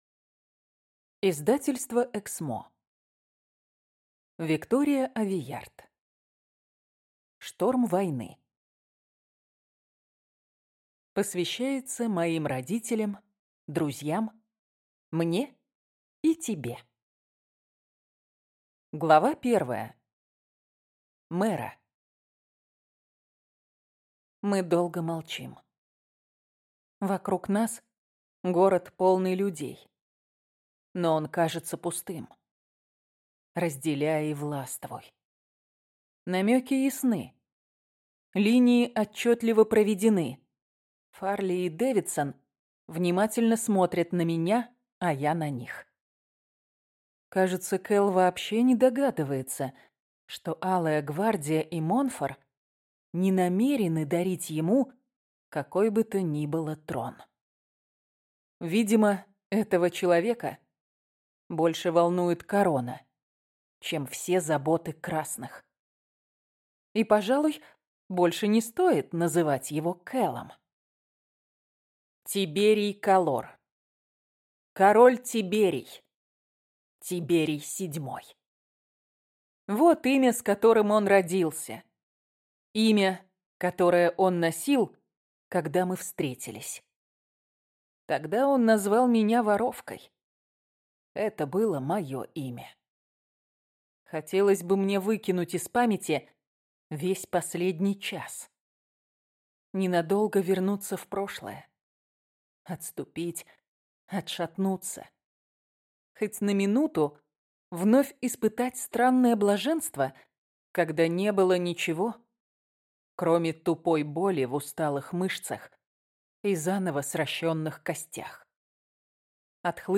Аудиокнига Горький шоколад | Библиотека аудиокниг